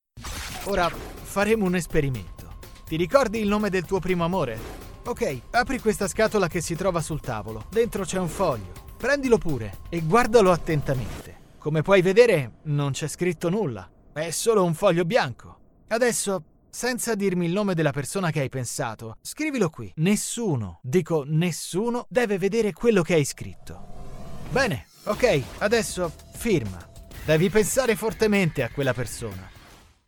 Voice Actor Storyteller and Advertising - Radio Speaker
Sprechprobe: Werbung (Muttersprache):
Some dubbing directors have defined my voice as expressive and versatile.
My voice is bright, expressive, energetic, fresh, sensual, warm, enveloping, suitable for dubbing (voice over - lip sync), reality, documentaries, videogames, audio guides, e-learning, audiobooks, commercials, jingles, podcast and radio. I also work through my Home-studio.